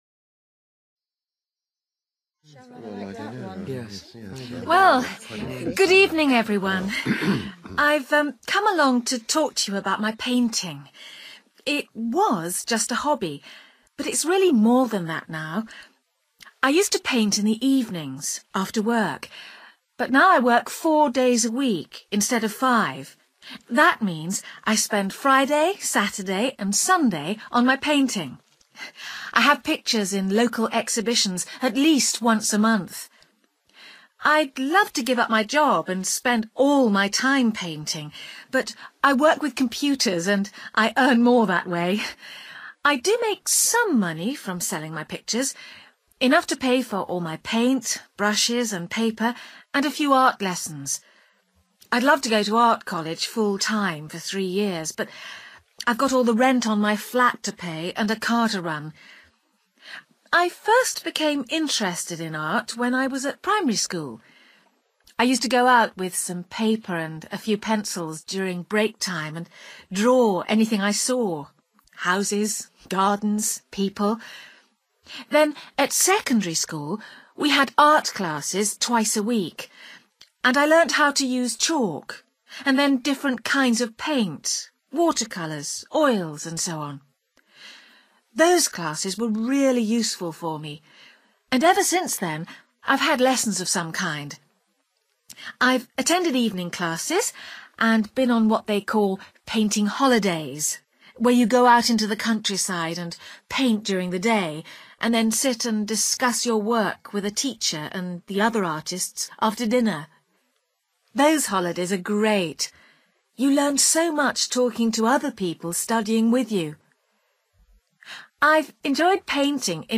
You will hear a woman